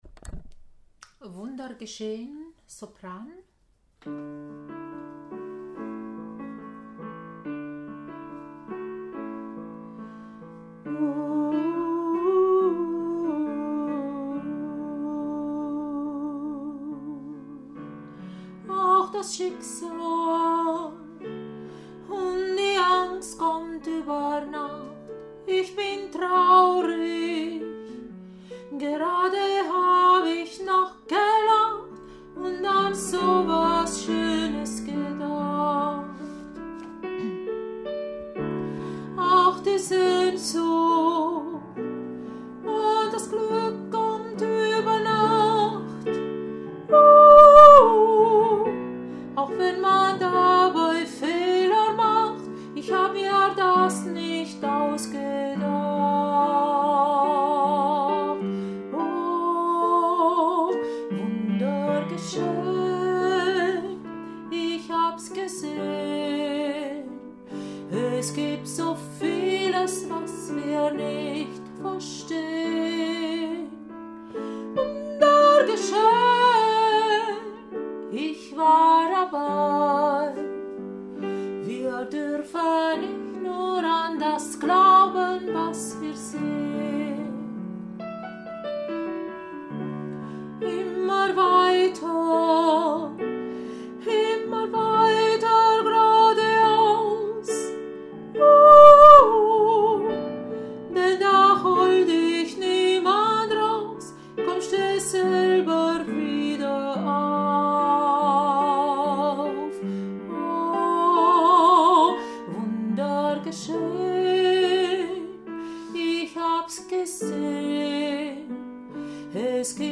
Wunder geschehen – Sopran – neu
Wunder-geschehen-Sopran-neu.mp3